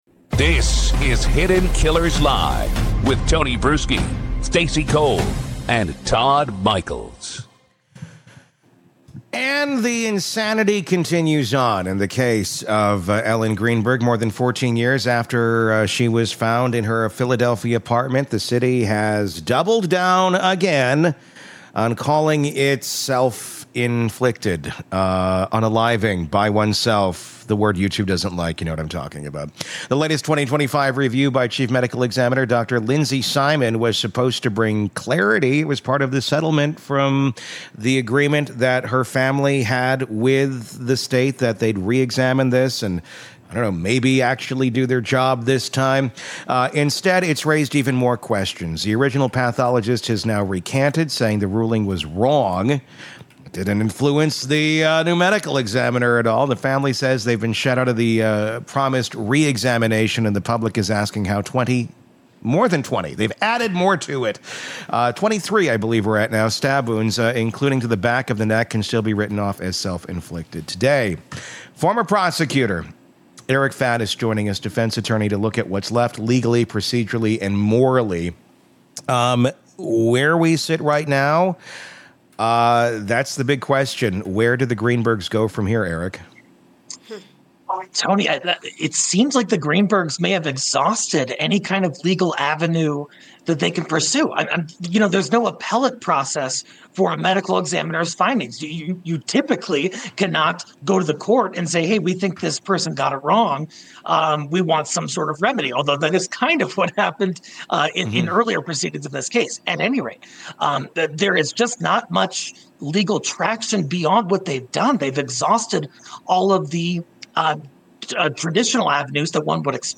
Expert Legal Breakdown